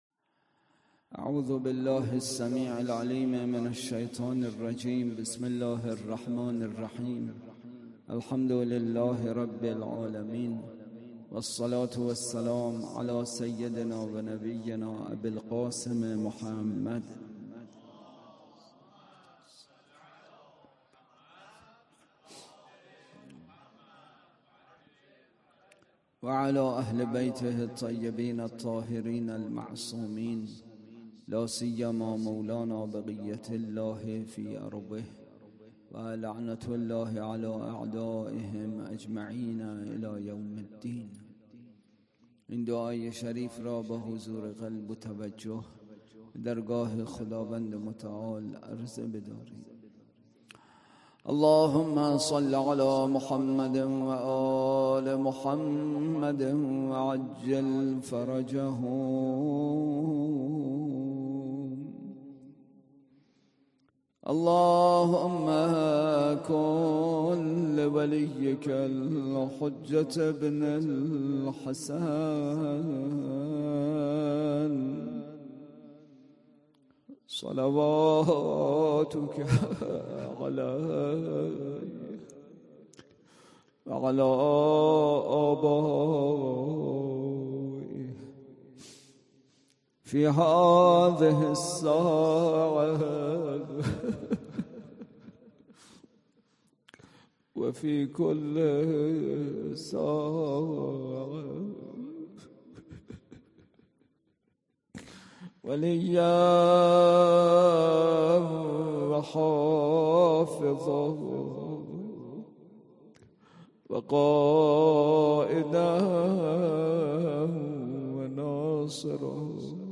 اطلاعات آلبوم سخنرانی